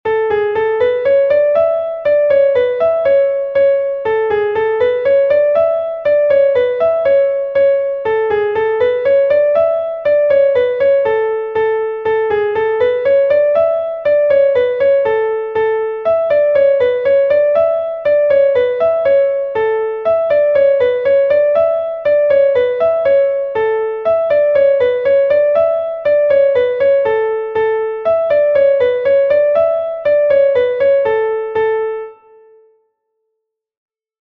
Rond